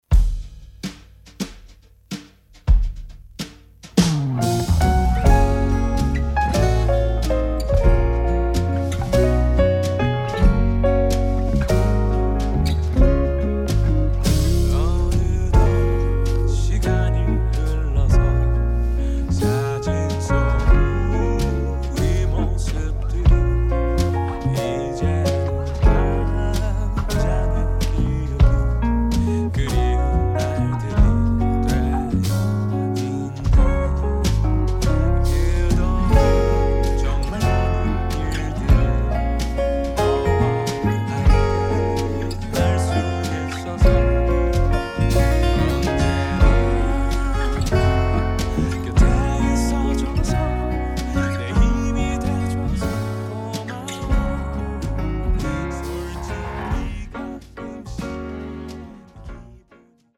음정 원키 4:22
장르 가요 구분 Voice Cut